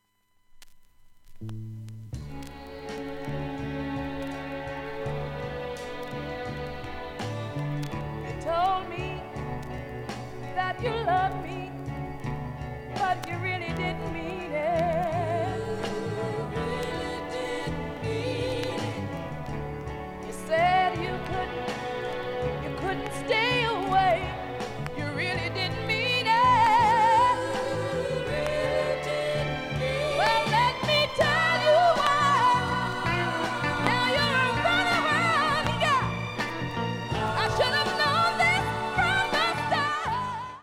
音質良好全曲試聴済み
B-1序盤にわずかなプツが8回出ます。